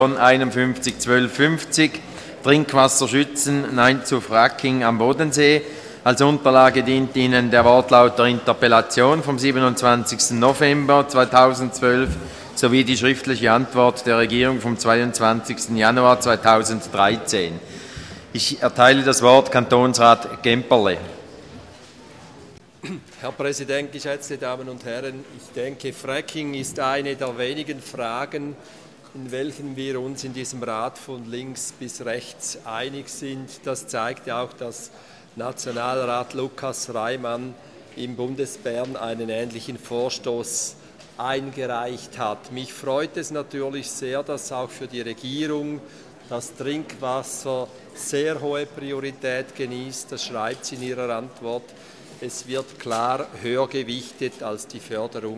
26.2.2013Wortmeldung
Session des Kantonsrates vom 25. bis 27. Februar 2013